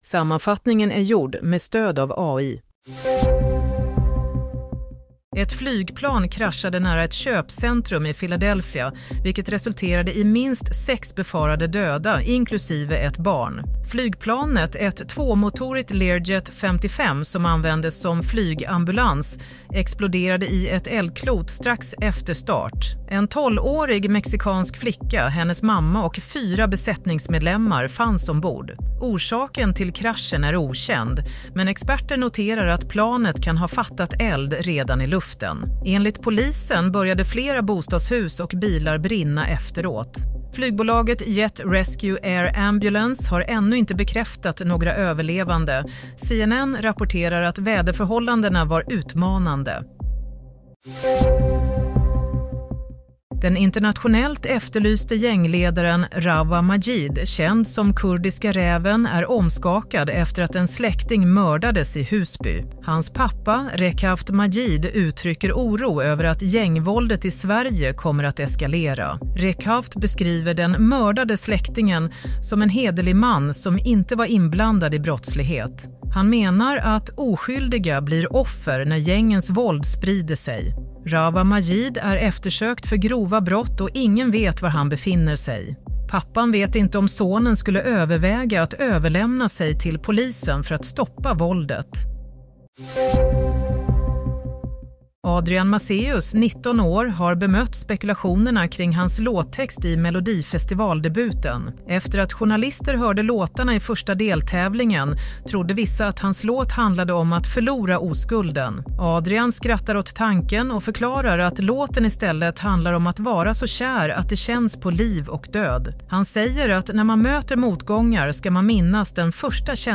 Nyhetssammanfattning – 1 februari 07:00